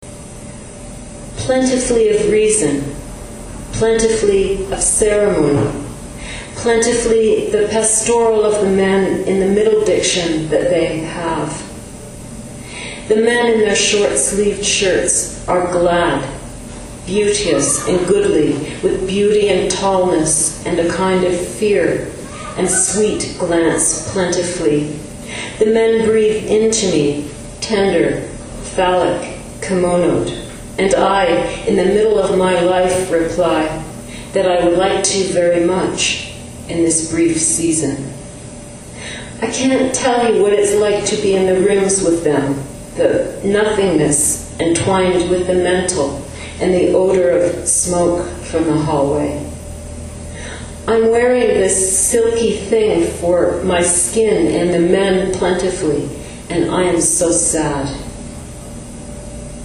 • Recording notes: lots of in-situ hiss; a duet with cooing infant toward the 20 second mark.